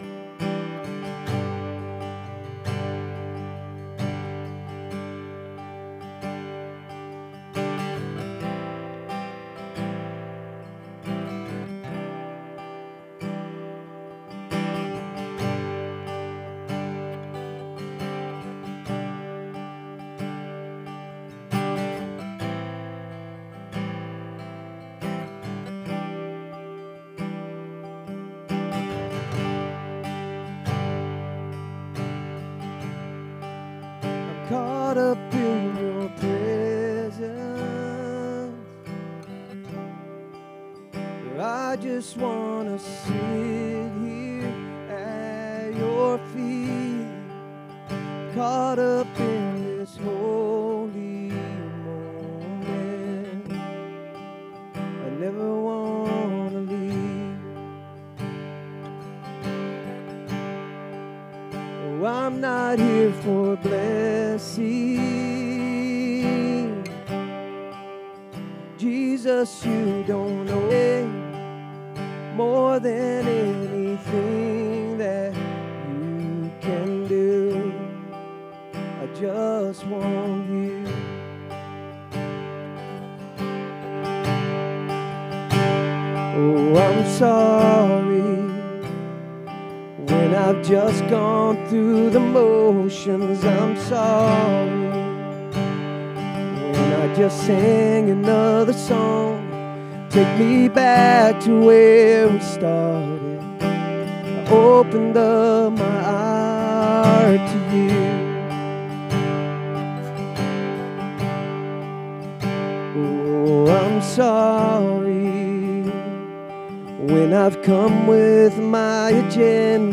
SERMON DESCRIPTION Jesus is at work doing a new thing in our life and yet so often we get stuck or return to the old thing that we know.